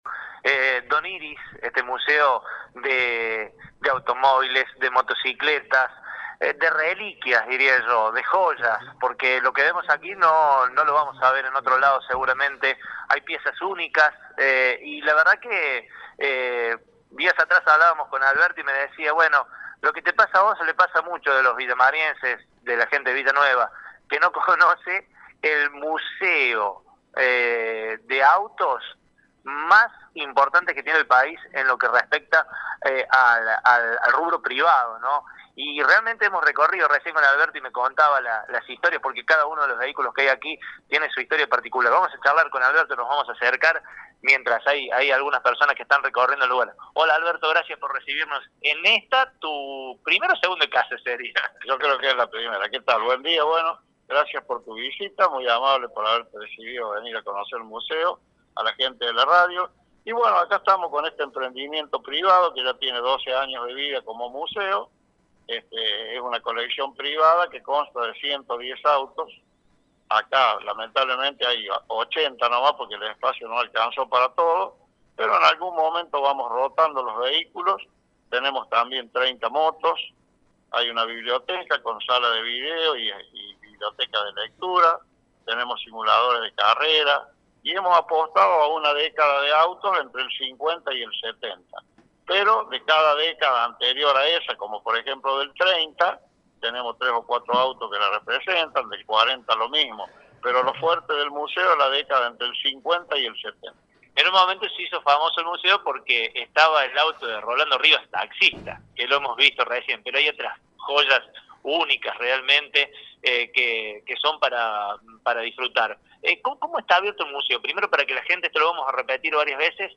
Con Radio Show nos dimos el gusto de poder recorrer el Museo Don Iris, espació ubicado en Avenida Presidente Perón 1323, donde además del Museo hay un café temático.